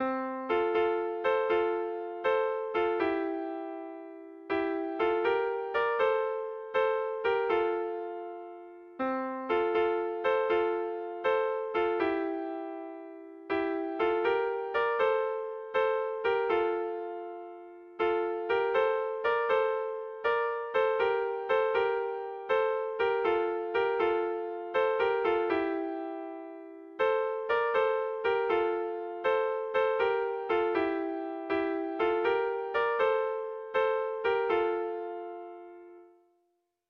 Sentimenduzkoa
ABDE